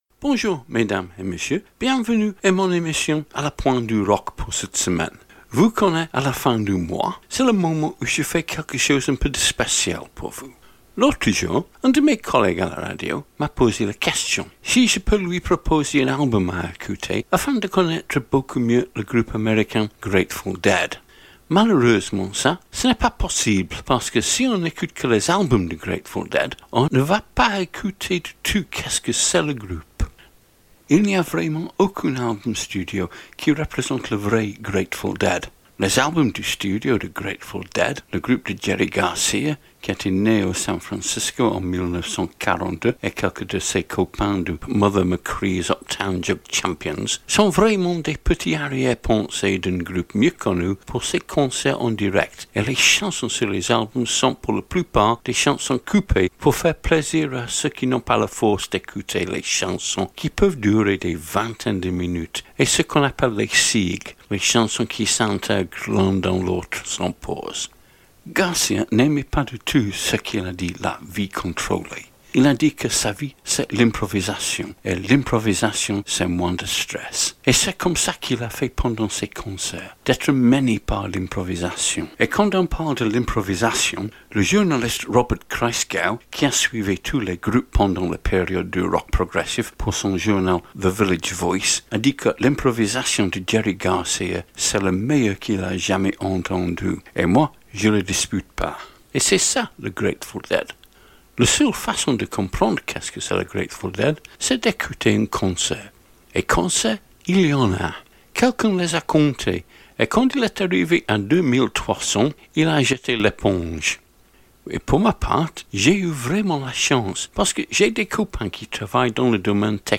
le rock anglophone des années 60 à 70